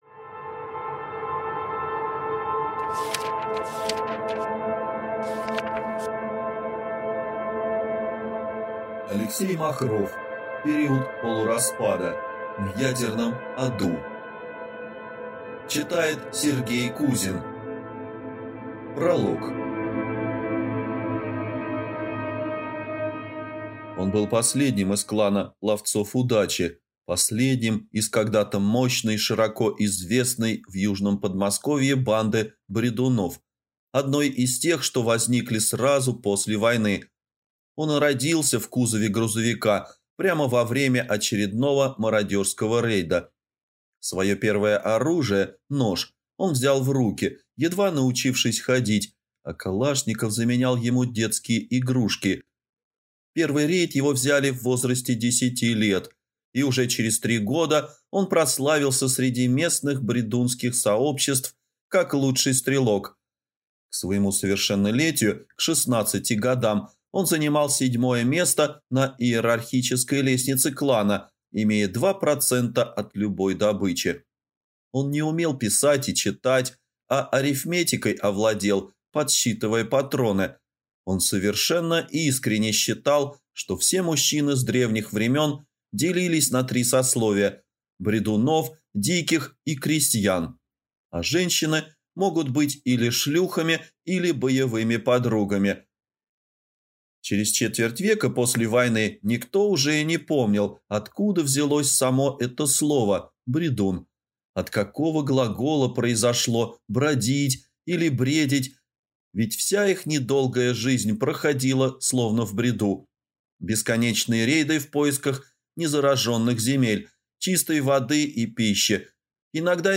Аудиокнига Период полураспада. В ядерном аду | Библиотека аудиокниг